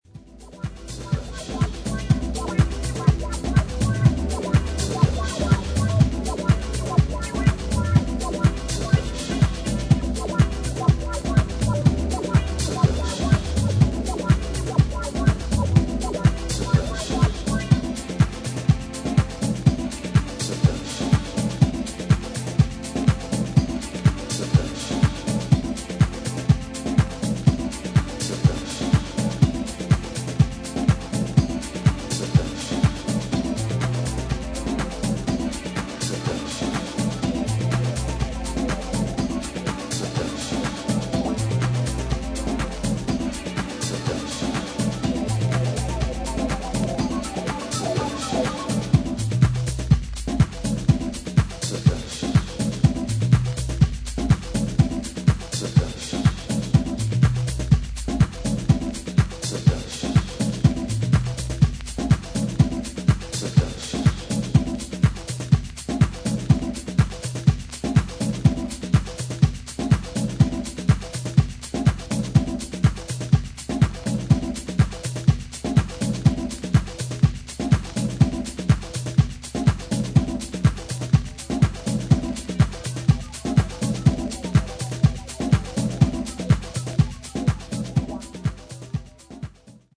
コンディション：VG＋ ※薄いスリキズあり、チリノイズあり。